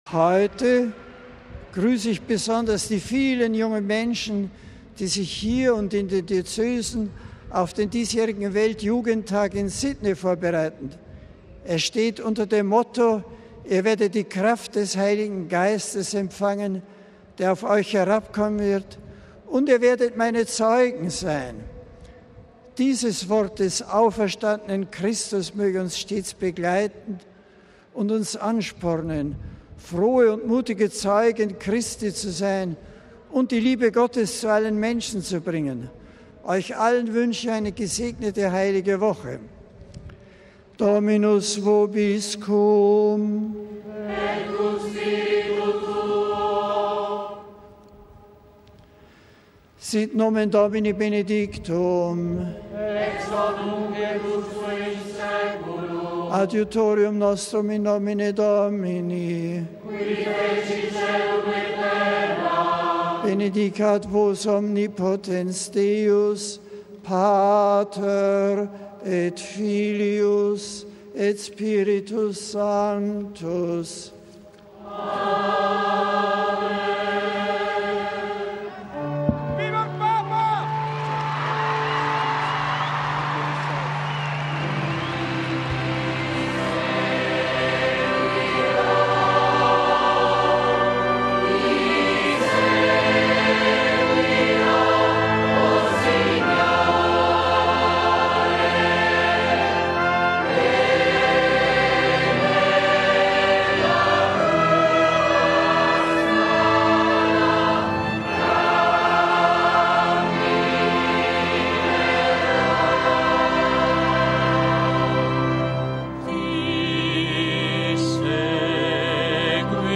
Angelus: Arrivederci in Sydney!
MP3 Papst Benedikt lenkte bei seiner Ansprache zum Angelusgebet den Blick nach Sydney in Australien, wo in diesem Jahr vom 15. bis 20. Juli der Weltjugendtag stattfindet. Er dankte den australischen Bischöfen, der dortigen Regierung und allen, die derzeit das Jugendtreffen vorbereiten.